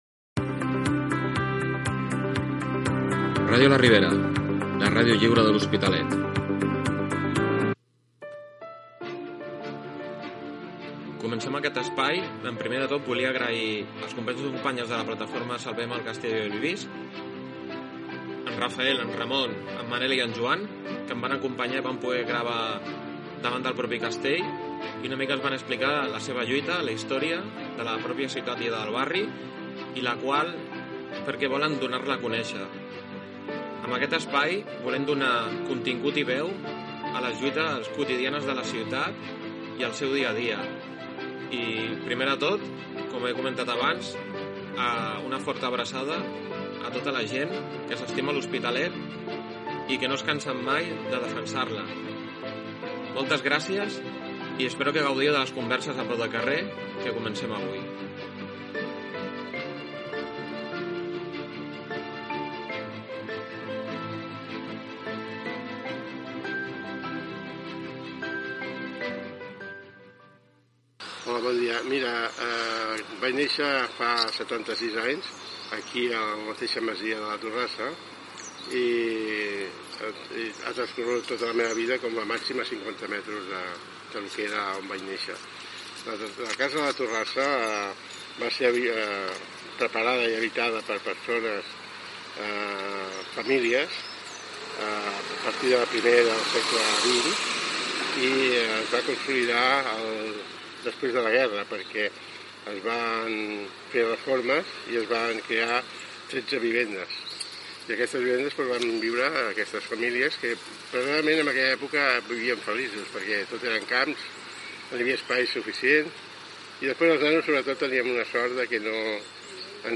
Indicatiu de l'emissora i espai dedicat a la plataforma veïnal "Salvem el castell de Bellvís"
Divulgació